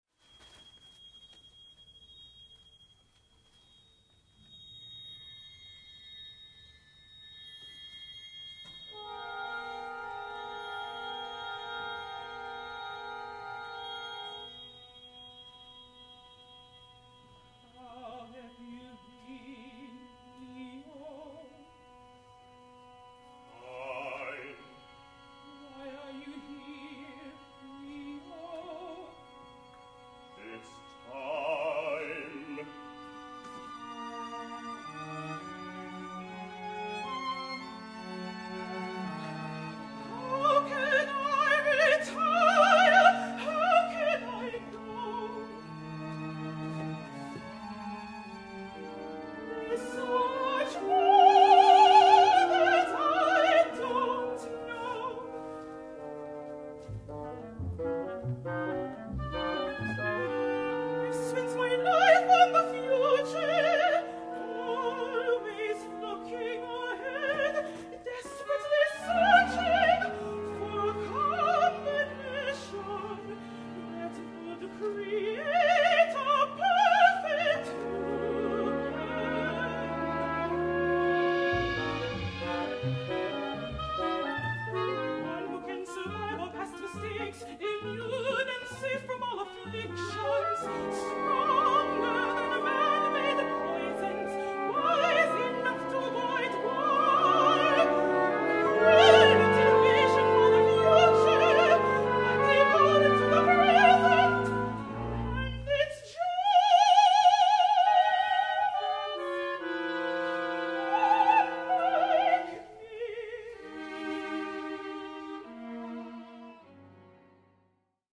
Instrumentation: An Opera in One Act